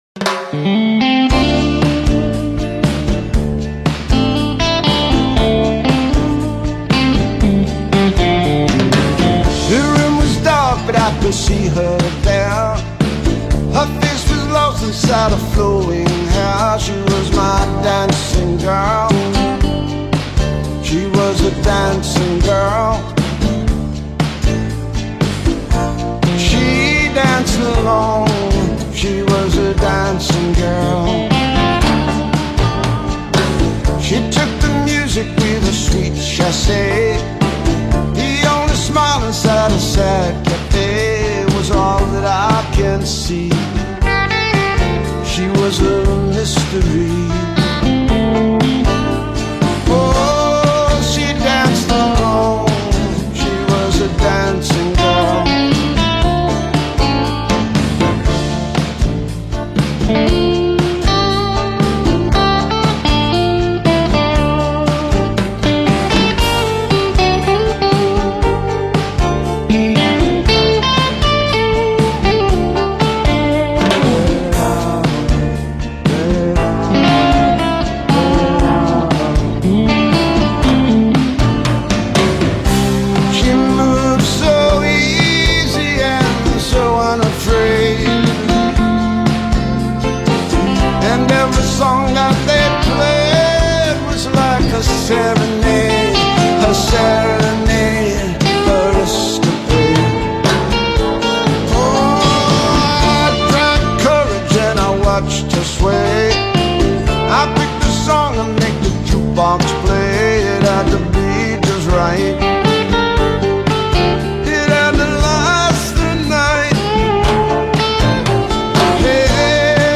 There is no rush here.